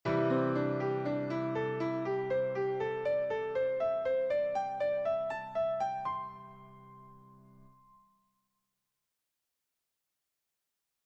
Improvisation Piano Jazz
Gamme Penta Majeure
Ascendant